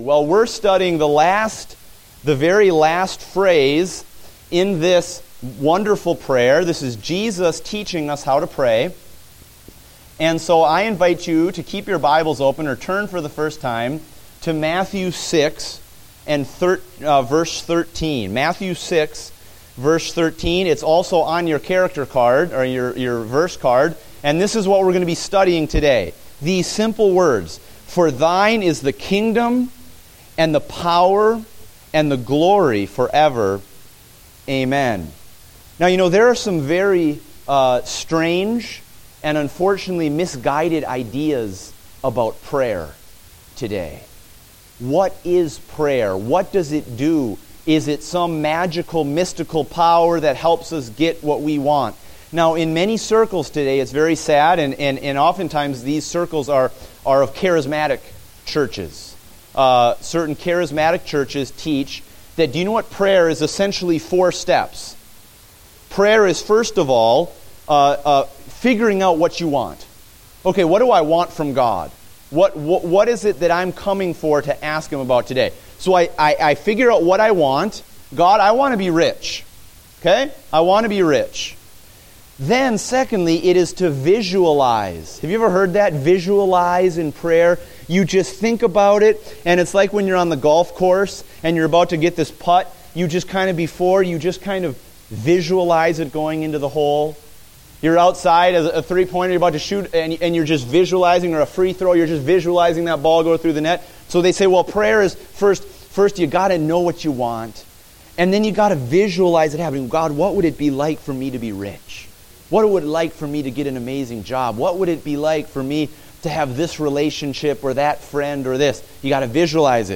Date: August 9, 2015 (Adult Sunday School)